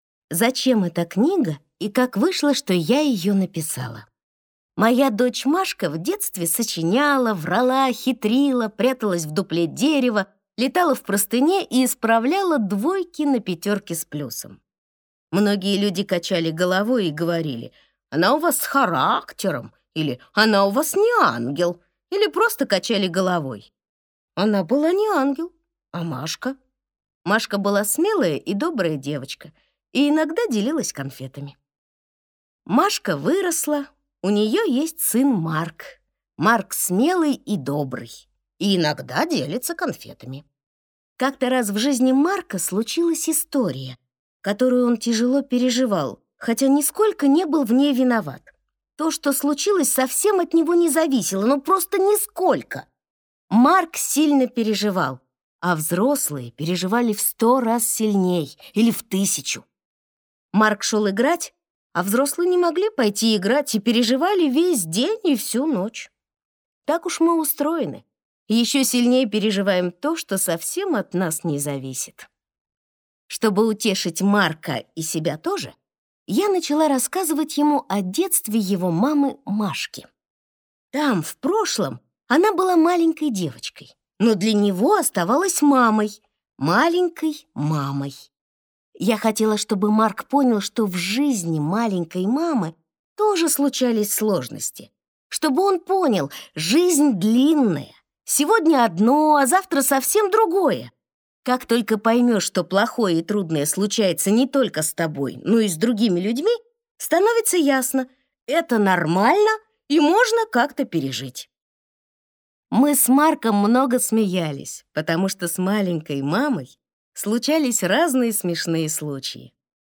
Аудиокнига Как мама была маленькой. Книга для дружбы детей и родителей | Библиотека аудиокниг